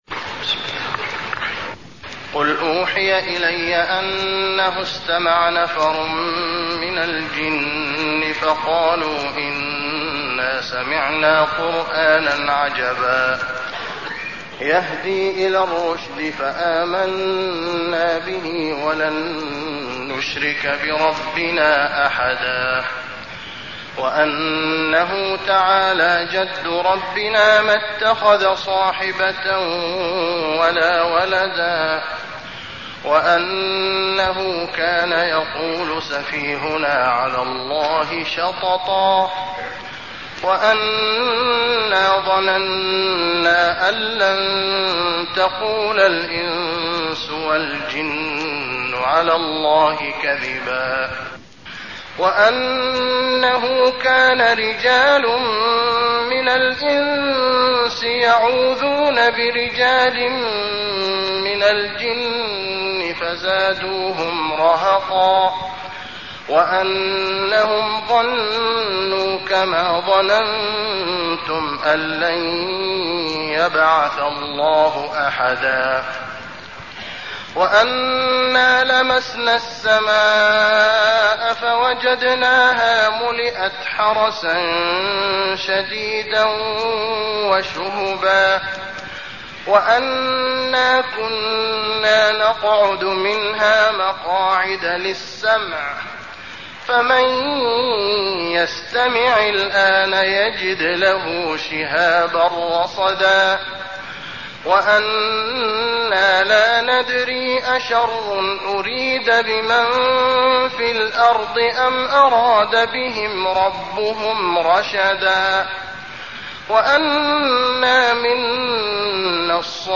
المكان: المسجد النبوي الجن